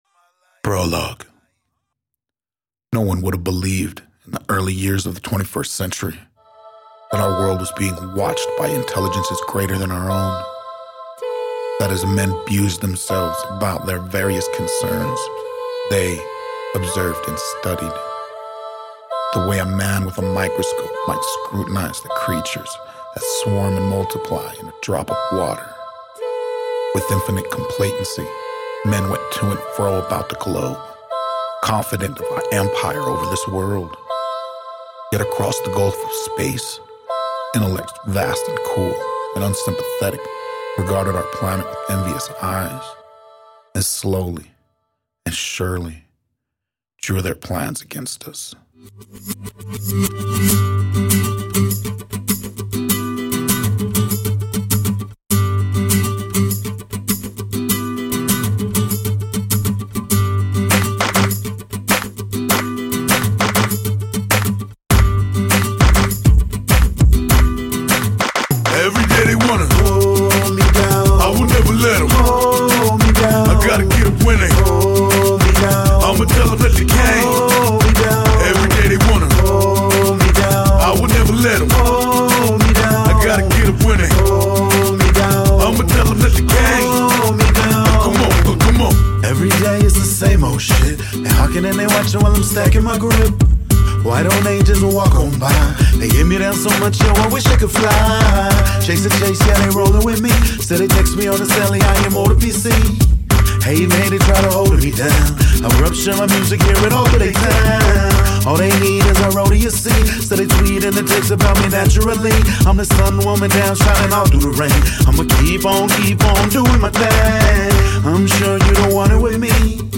Great combination on hiphop with a raggae vibe.